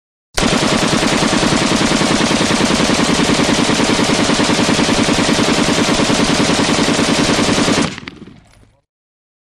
Звуки автоматной очереди
Вариант погромче